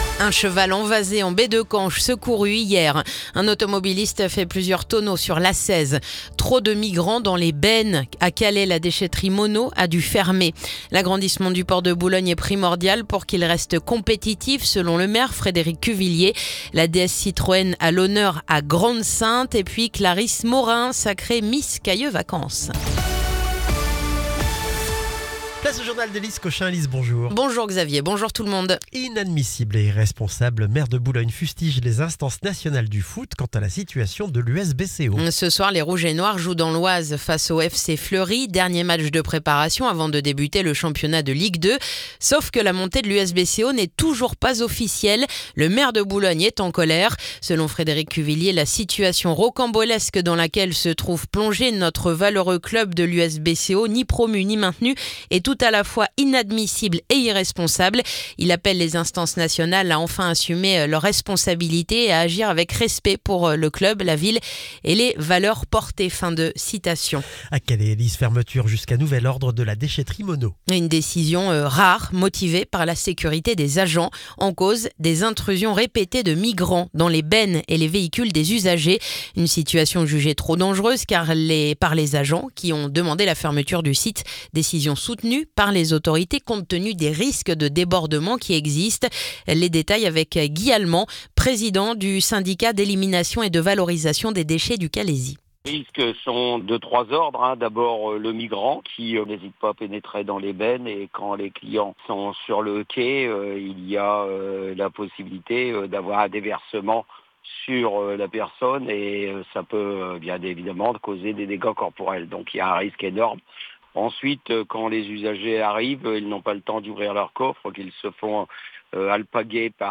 Le journal du vendredi 1er août